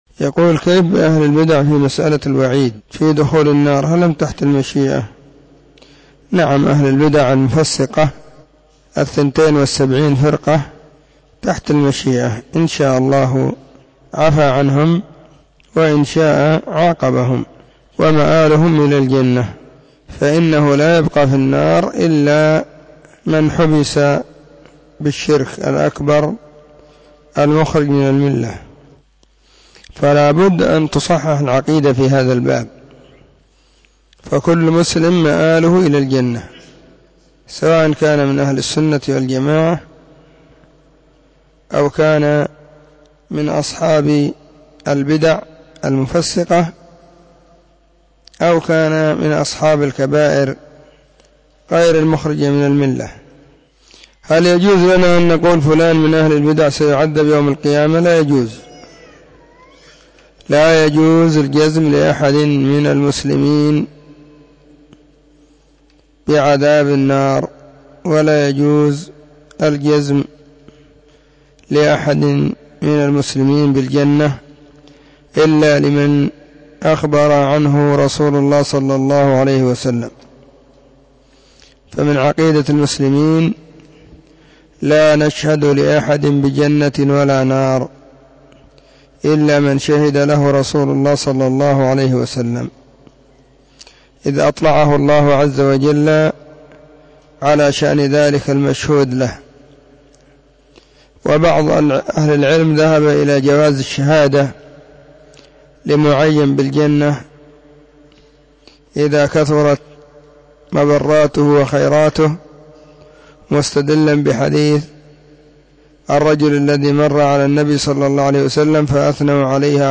فتاوى الثلاثاء 27 /ربيع الاول/ 1443 هجرية. ⭕ أسئلة ⭕ -7
📢 مسجد الصحابة – بالغيضة – المهرة، اليمن حرسها الله.